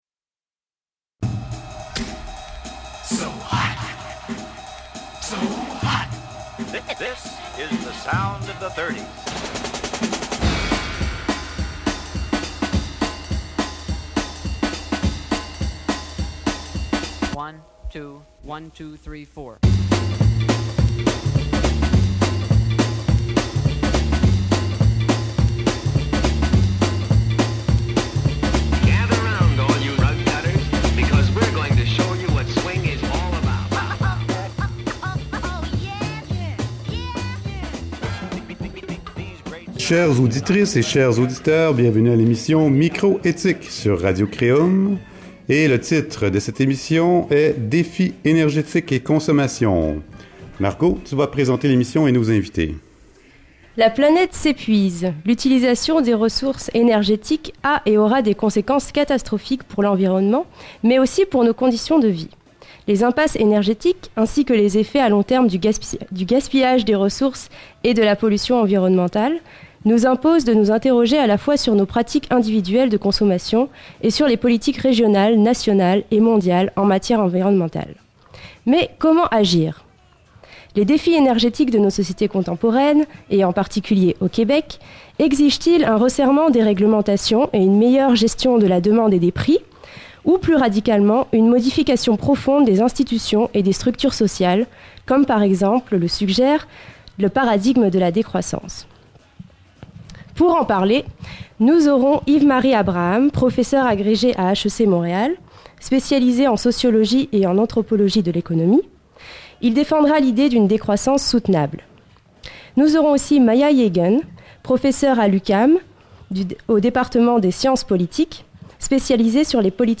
Toute la musique que vous entendrez sur cette épisode est sous licence Creative Commons.